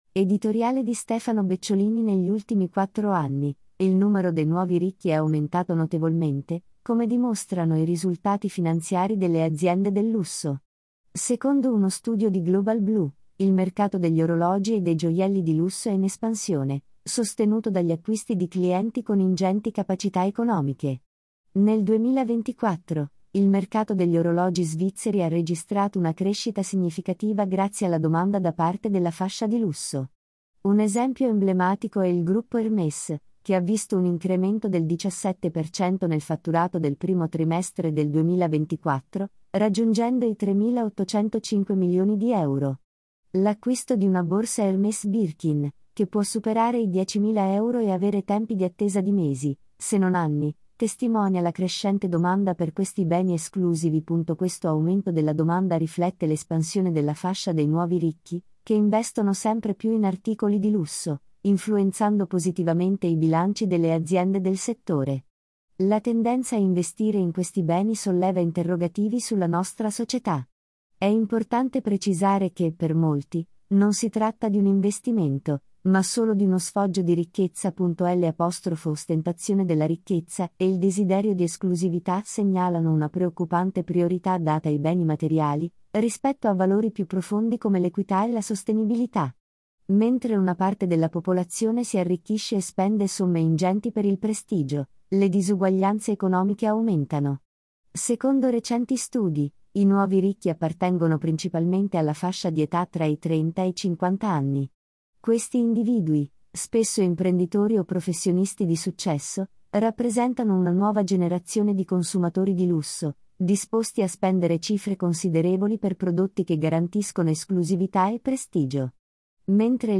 Editoriale